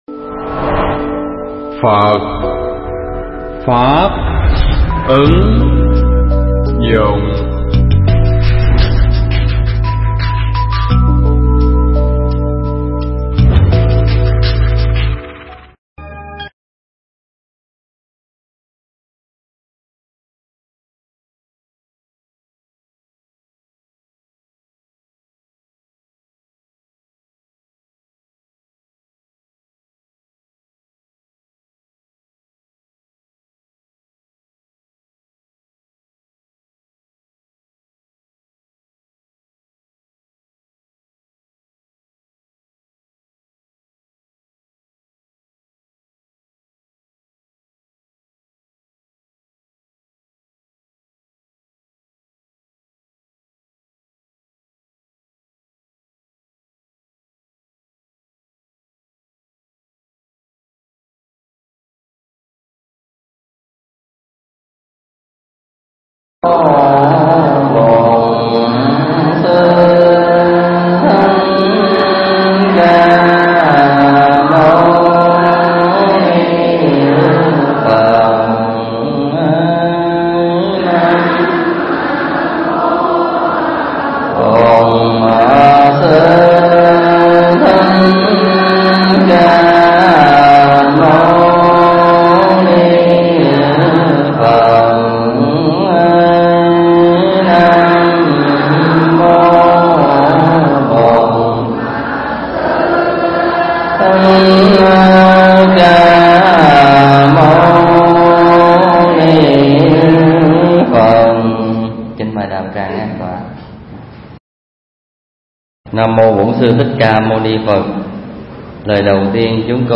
Tải mp3 pháp thoại Kinh Bát Đại Nhân Giác 7 (hết)
tại chùa Ấn Quang